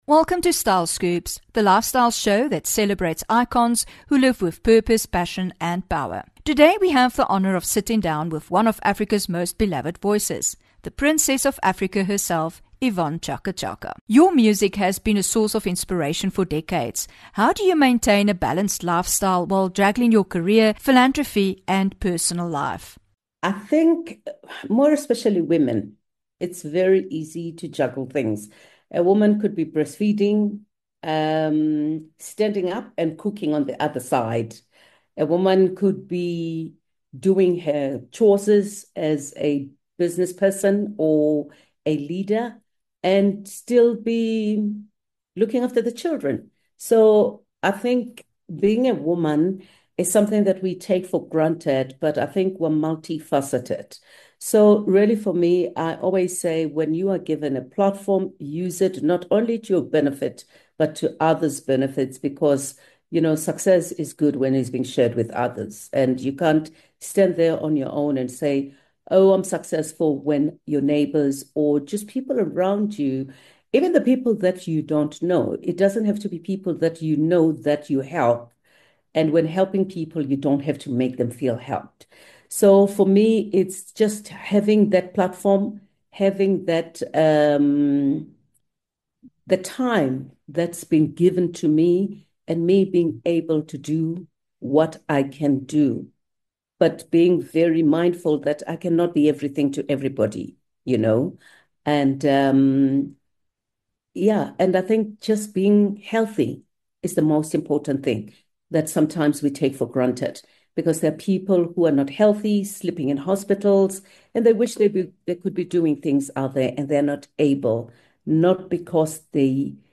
6 May INTERVIEW: YVONNE CHAKA CHAKA